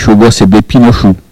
parole, oralité
Locution